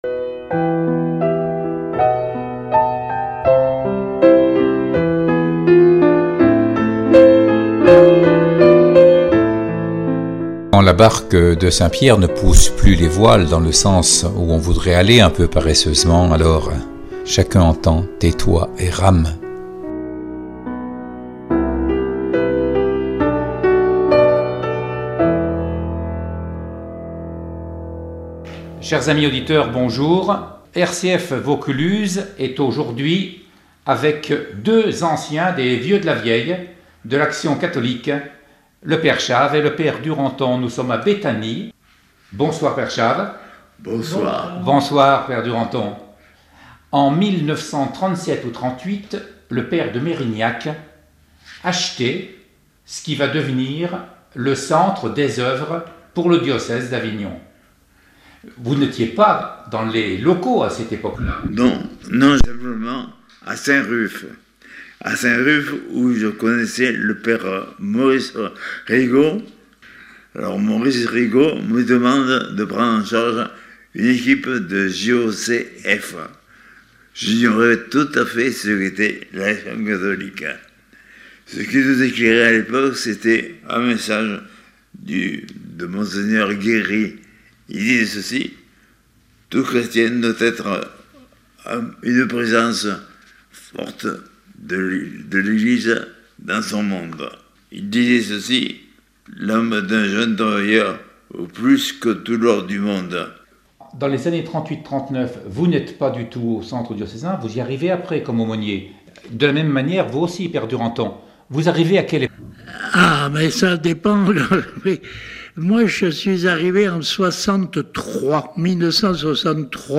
Ils sont nonagénaires, et l’âge est bien là .Il faut parler haut et articuler, reformuler certes, mais quand il est question de 1963, de la Maison des Œuvres à Avignon, l’œil pétille ; alors ils se coupent la parole et retrouvent leur peau de trentenaires, fin heureux d’être ensemble, en dehors du jeu des paroisses pour animer leurs mouvements : JOC, ACGF, ACGH, JAC, retraités, jeunesse…